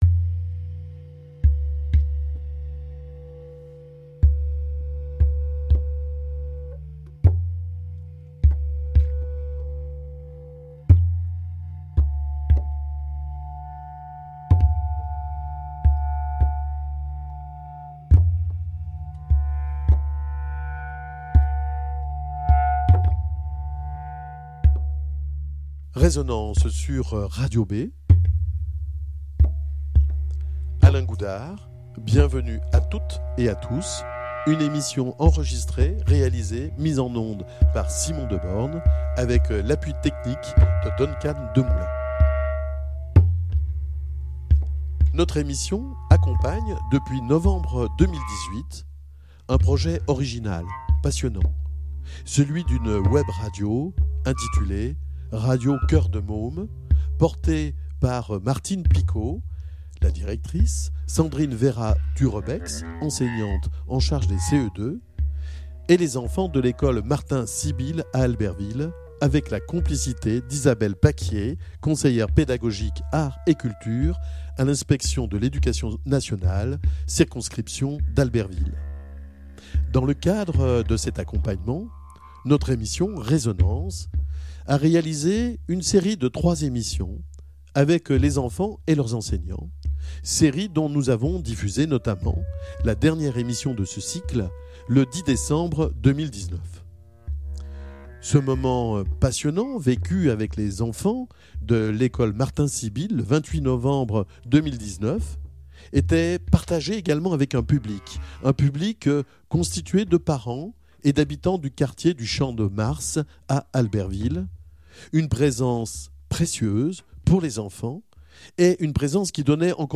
Il y a avait, pour ces mamans, un besoin de pouvoir exprimer leur fierté de voir leurs enfants dire, exprimer des idées, soulever des questionnements et aussi l'envie de pouvoir dire à leur tour, de partager leurs sentiments, leur réflexion et de faire entendre une parole. Cette discussion s'est poursuivie, autour de nos micros et c'est ce que nous vous proposons de partager dans cette émission d'aujourd'hui.